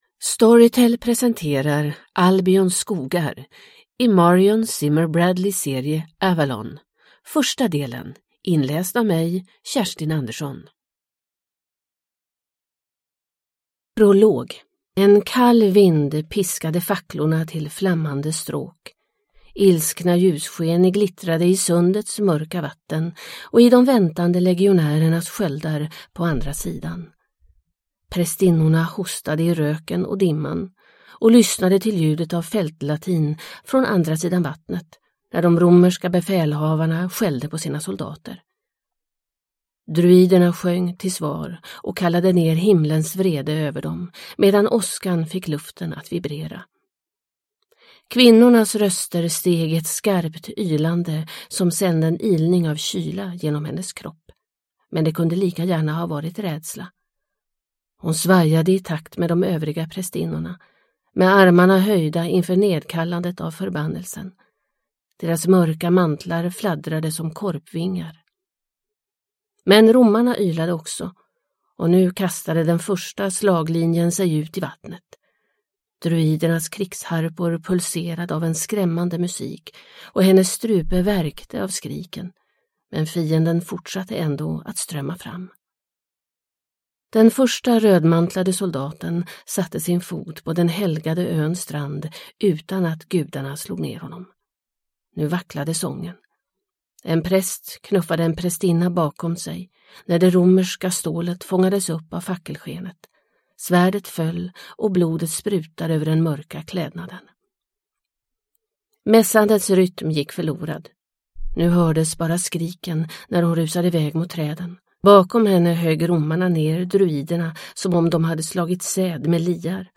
Albions skogar – del 1 – Ljudbok – Laddas ner